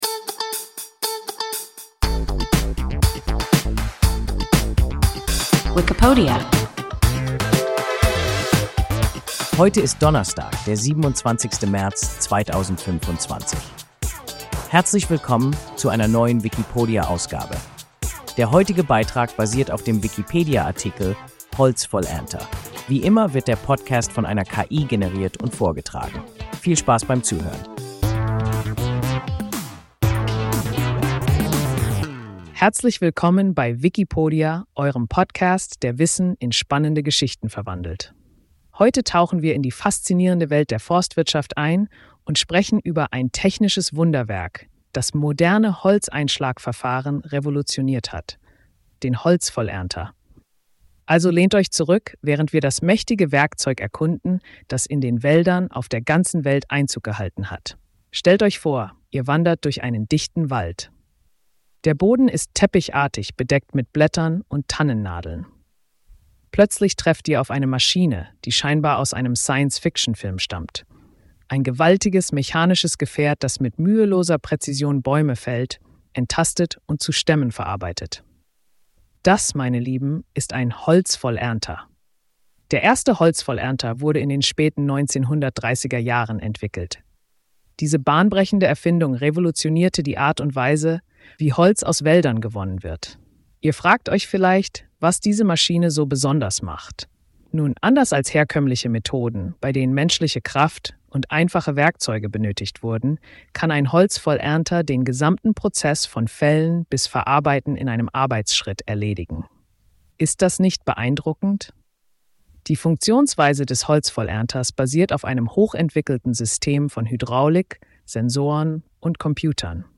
Holzvollernter – WIKIPODIA – ein KI Podcast